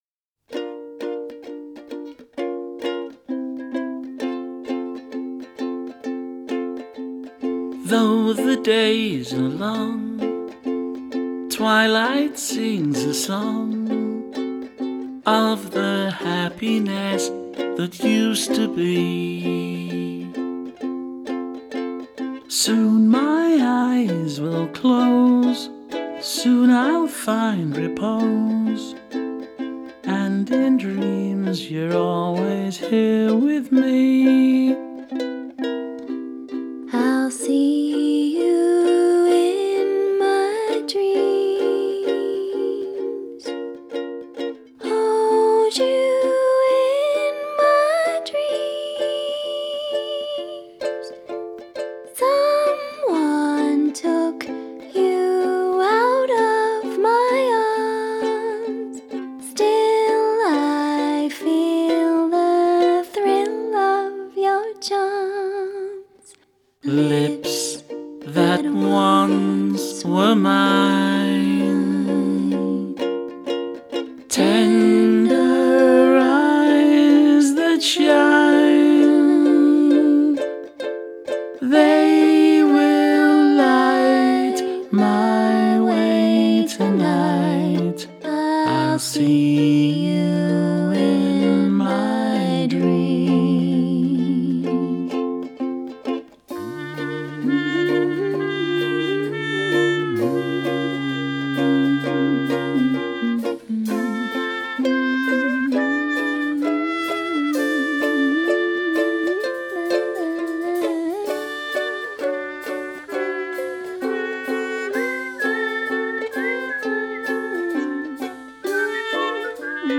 A tender lullaby of reunion in the world of sleep and dreams
TraditionalAcoustic